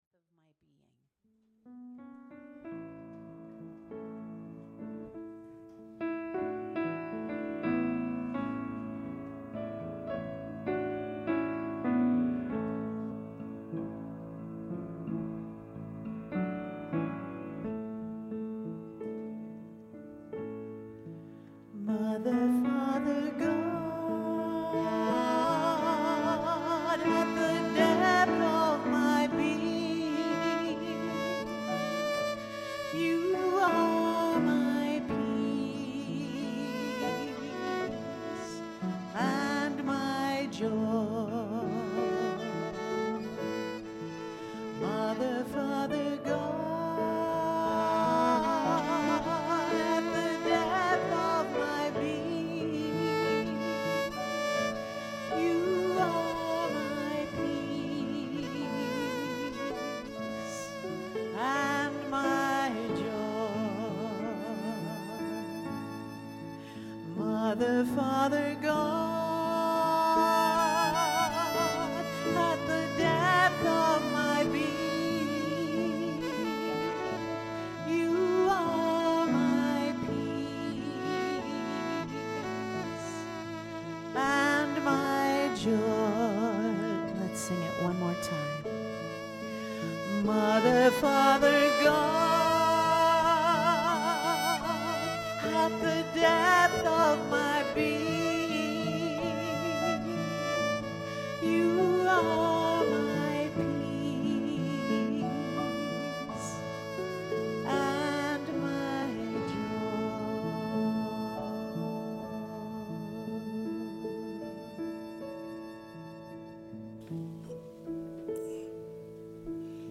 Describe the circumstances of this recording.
The audio recording (below the video clip) is an abbreviation of the service. It includes the Meditation, Lesson and Featured Song.